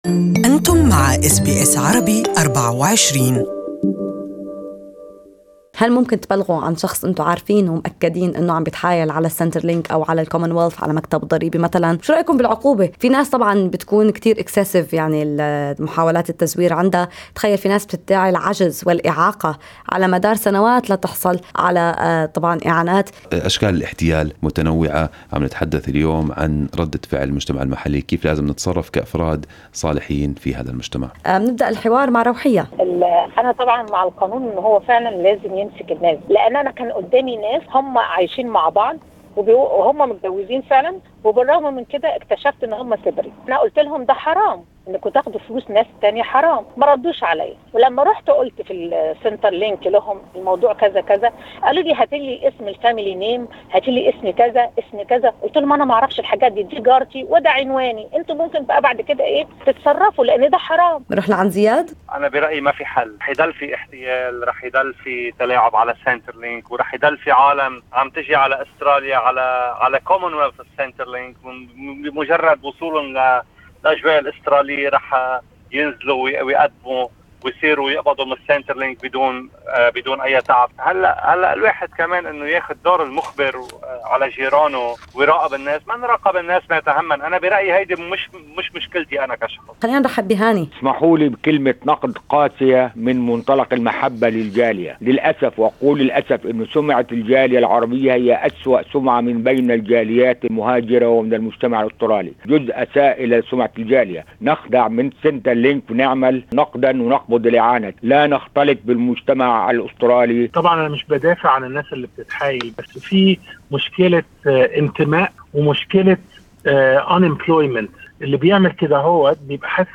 عينة من آراء أفراد الجالية العربية حول موضوع التبليغ عن حالات الاحتيال على دائرة الخدمات الاجتماعية.